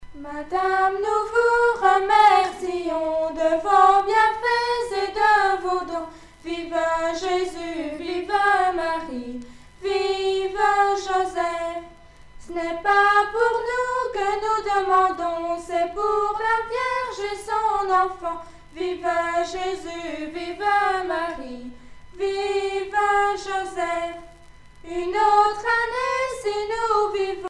circonstance : mai
Pièce musicale éditée